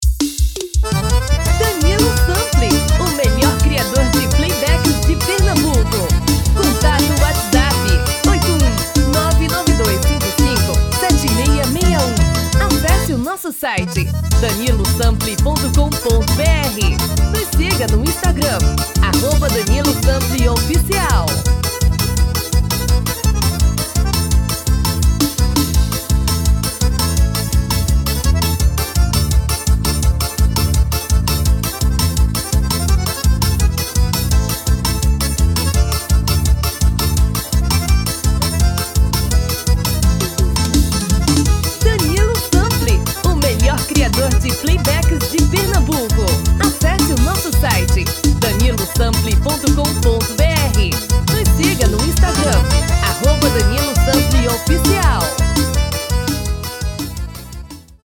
DEMO 1: tom original DEMO 2: tom feminino